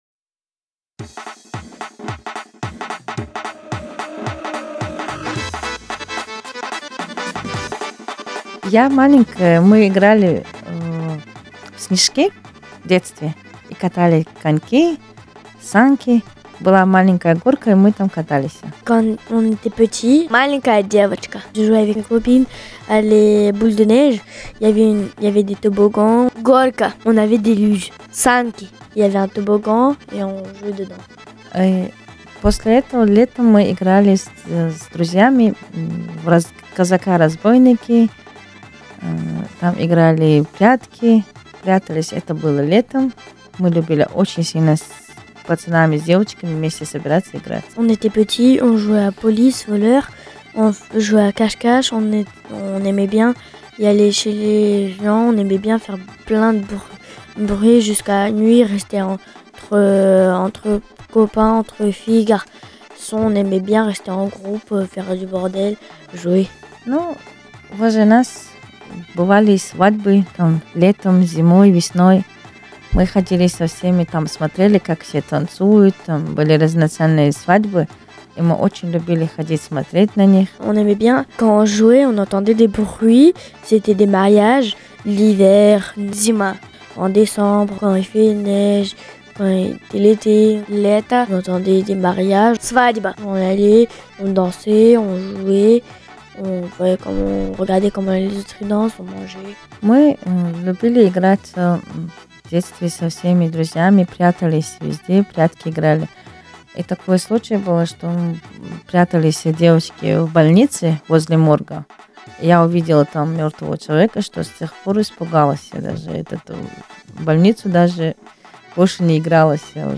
Durant l’été 2023 plusieurs familles Brestoises plurilingues, avec l’accompagnement de Radio U ont enregistré des histoires. Traditionnelles, autobiographiques, inventées, réarrangées, le champ des possible était ouvert.